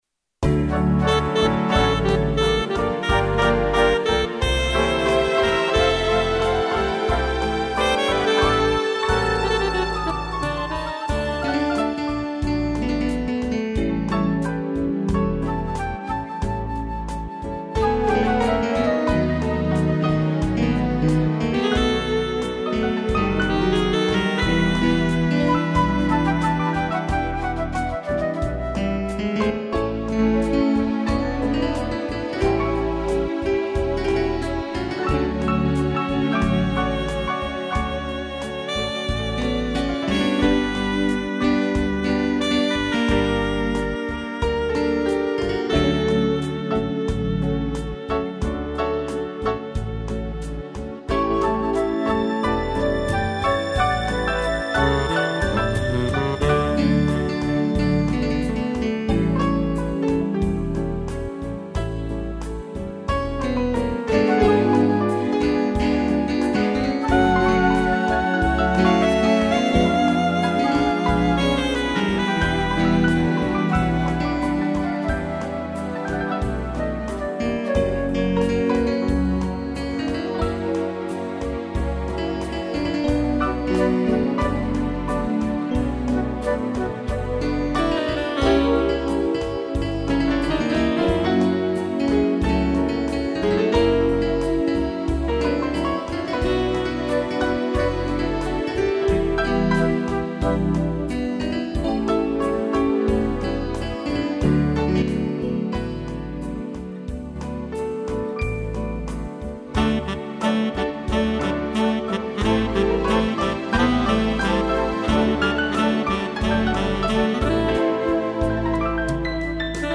piano, sax, flauta e cello
(instrumental)